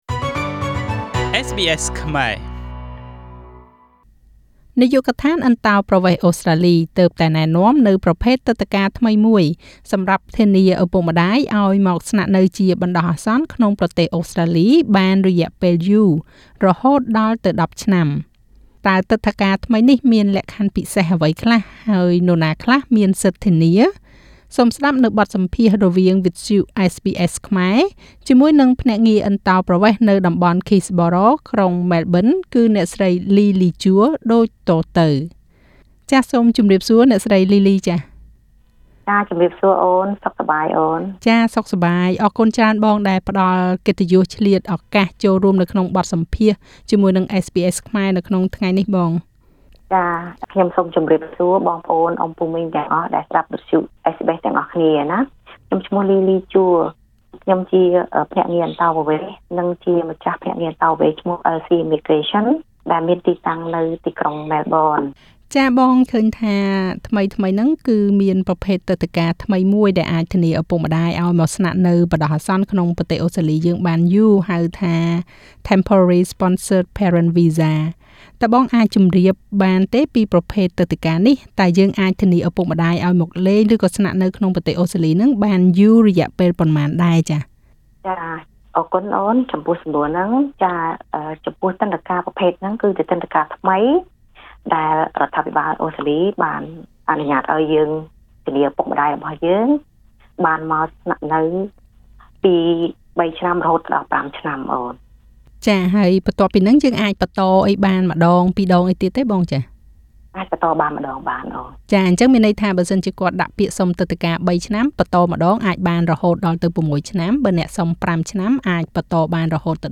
សូមស្តាប់បទសម្ភាសន៍រវាងវិទ្យុ SBSខ្មែរ